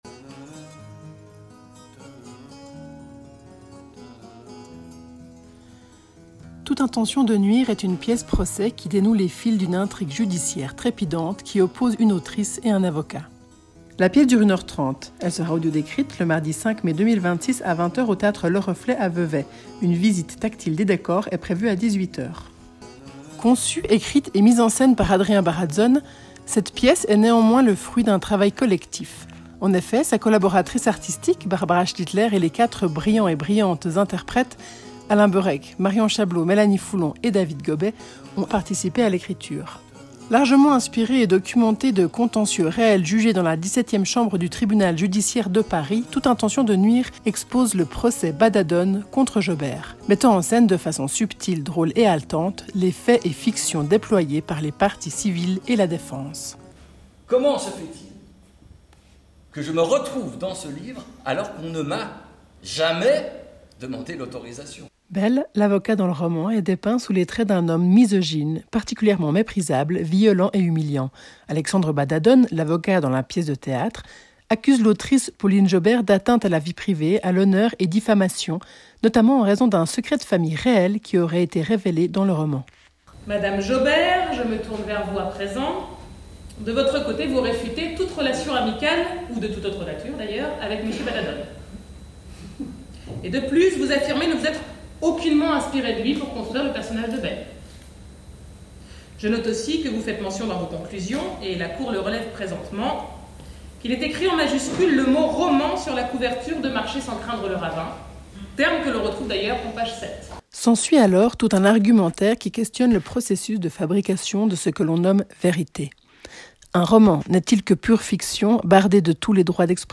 Audiodescription
Bande annonce